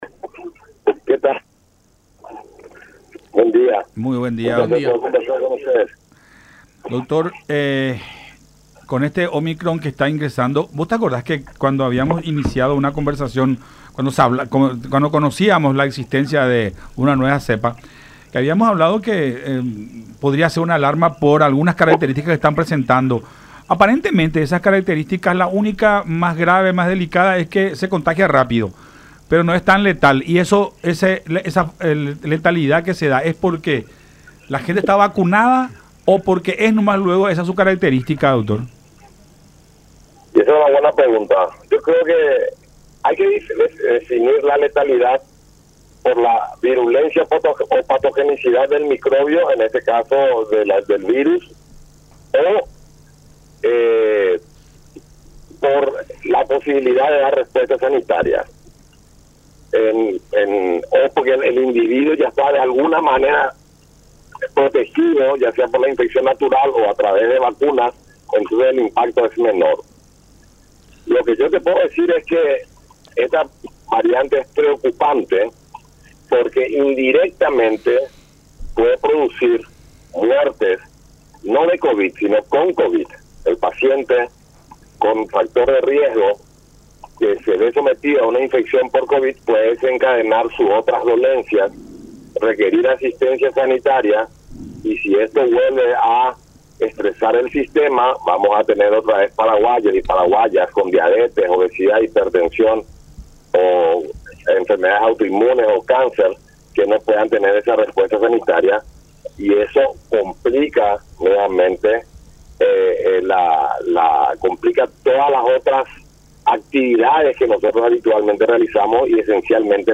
en conversación con Enfoque 800 a través de La Unión.